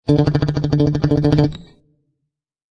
Descarga de Sonidos mp3 Gratis: guitarra 22.